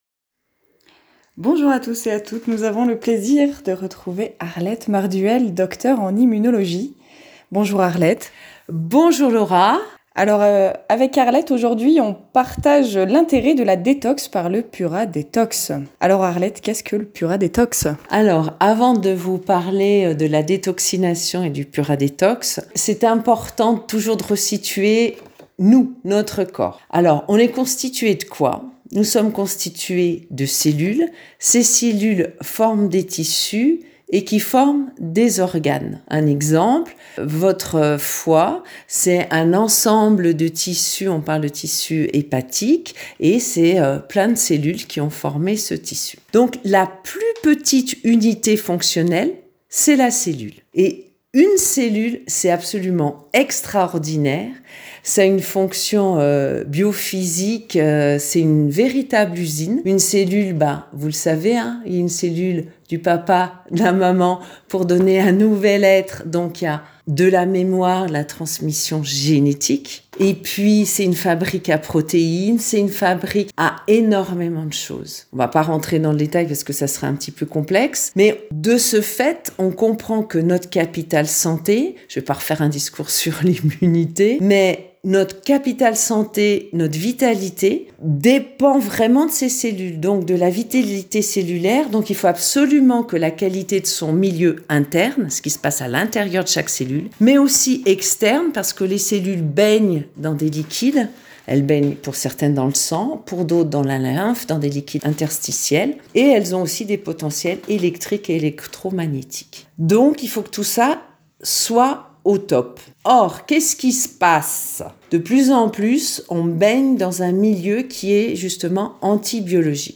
Séance de pura detox en live !